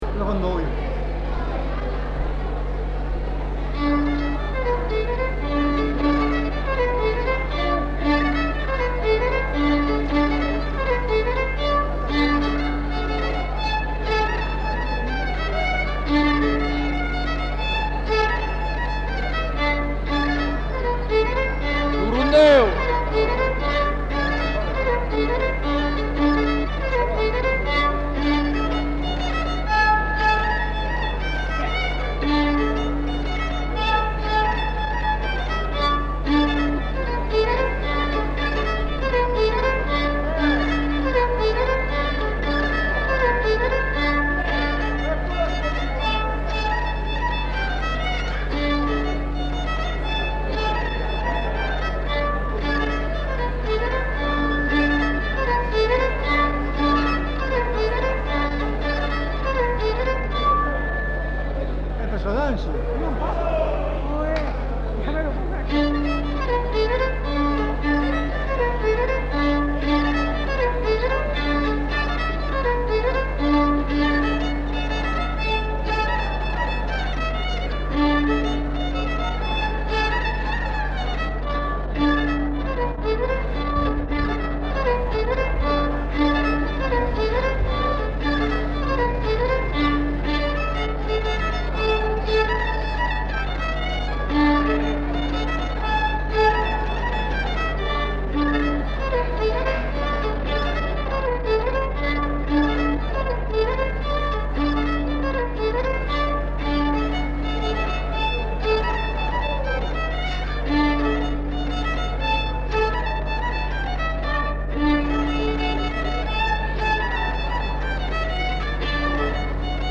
Rondeau
Aire culturelle : Savès
Lieu : Samatan
Genre : morceau instrumental
Instrument de musique : violon
Danse : rondeau